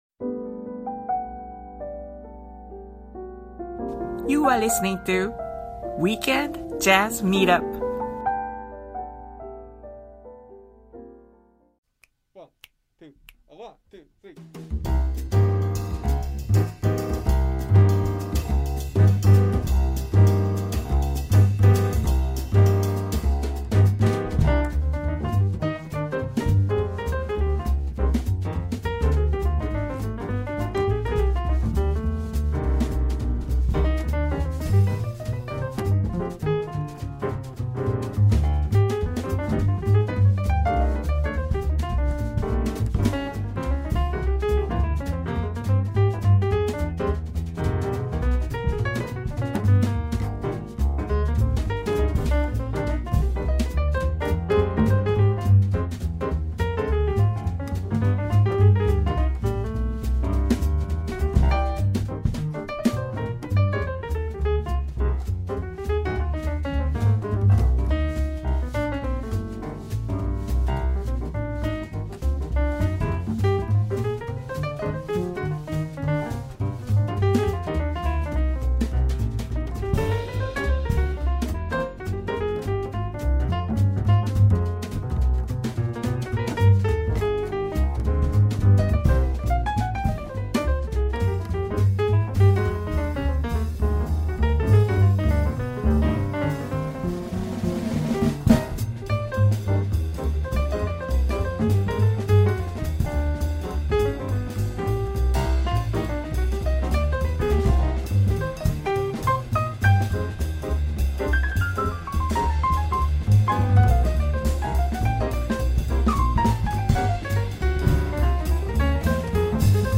“Weekend Jazz Meetup” and “９匹の羊” are weekly music promotional programs dedicated to Japanese jazz artists, organized by a fellow Japanese artist.